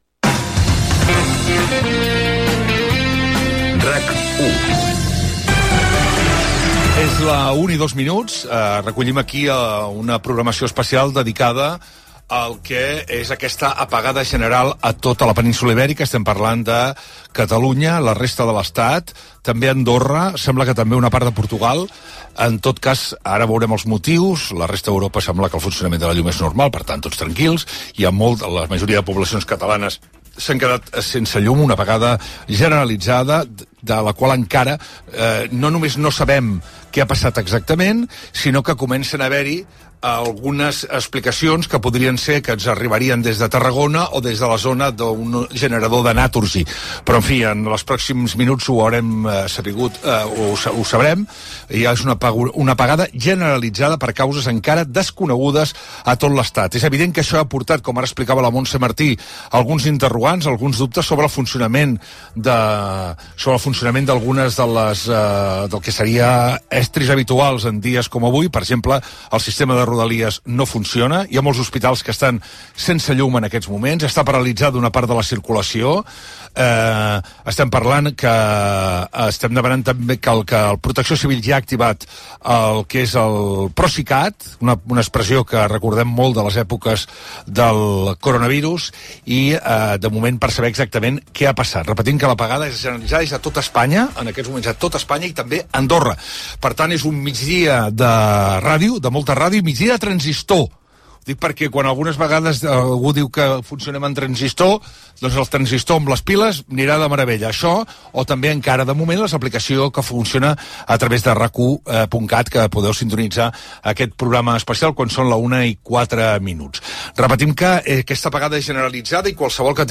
Indicatiu de l'emissora, inici del programa informatiu especial sobre l'apagada general de subministrament elèctric a la Península Ibèrica Gènere radiofònic Informatiu